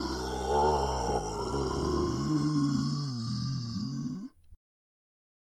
zombie_angry.ogg